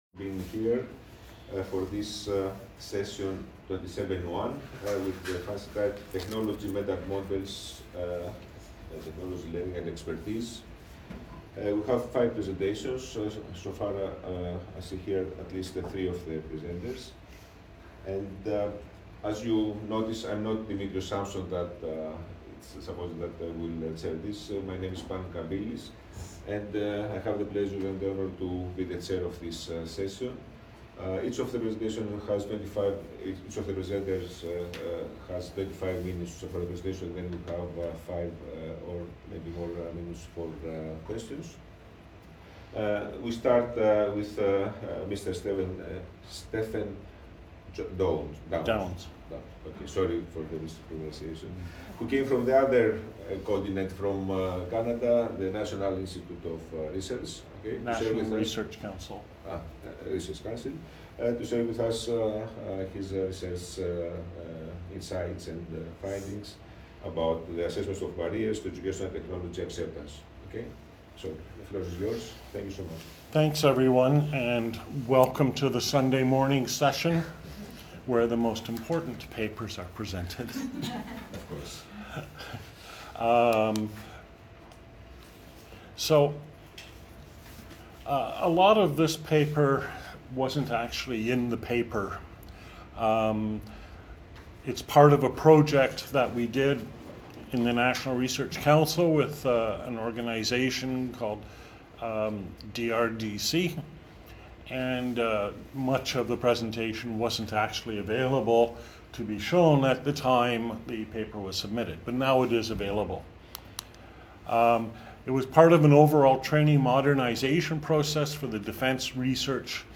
This presentation summarizes major factors - technology acceptance models, risk matrices, validity measures - used to assess a survey of instructors on their acceptance of instructional technology.